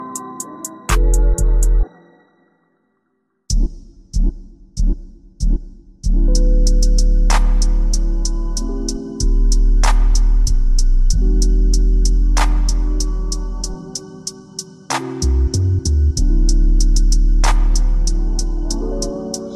Bring back old carspotting sounds